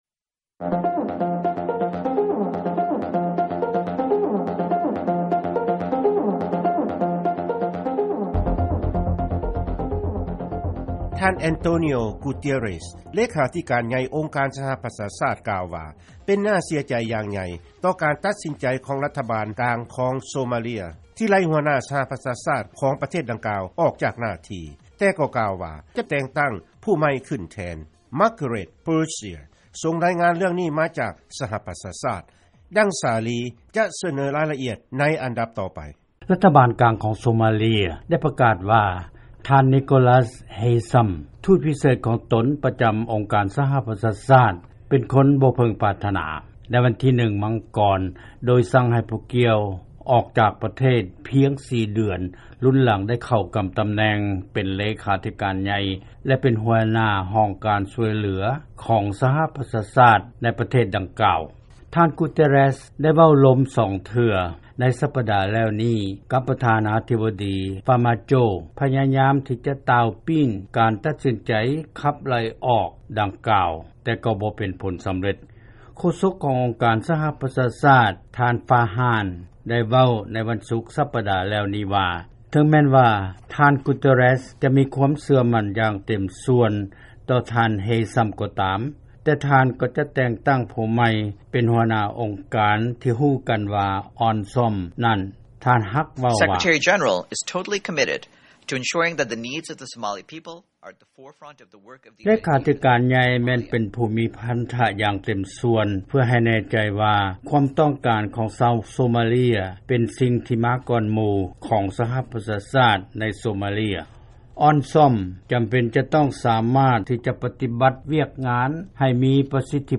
ເຊີນຟັງລາຍງານກ່ຽວກັບໂຊມາເລຍ